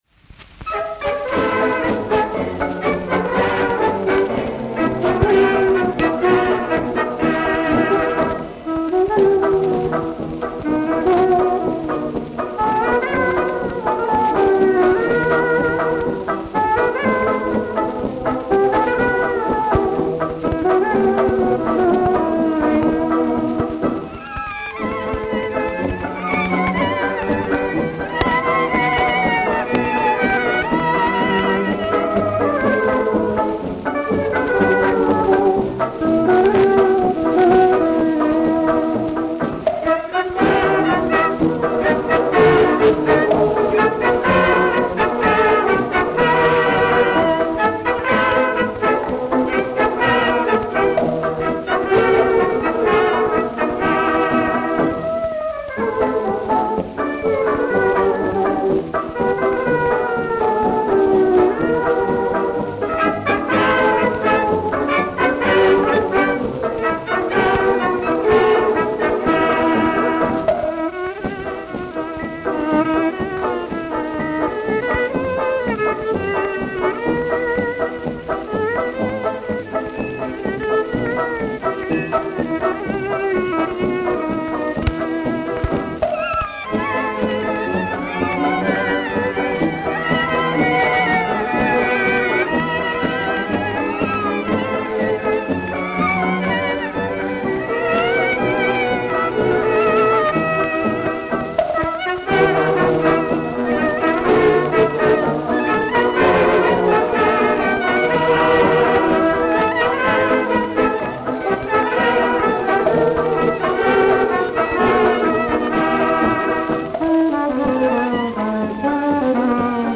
Место записи - Берлин, 1929 год
Шипит, трещит... но до чего приятно слушать.